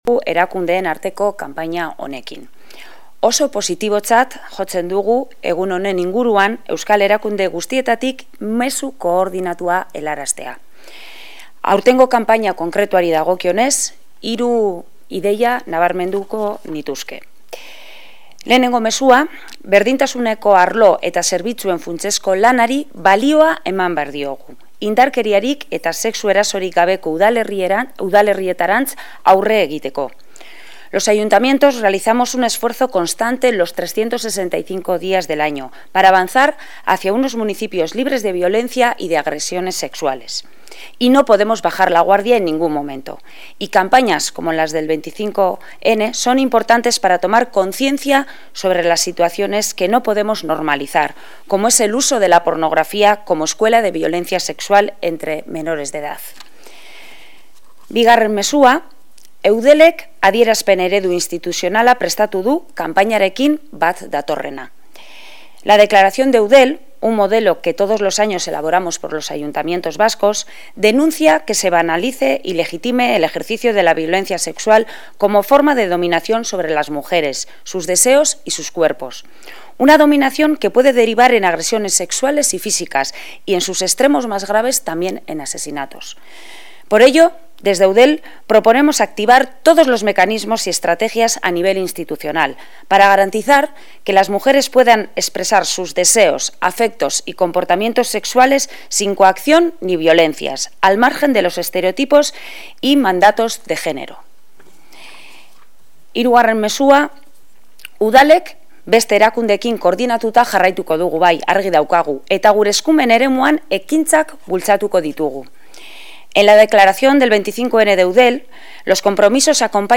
Esther Apraiz-en, EUDELeko Elkateburuaren, hitzak.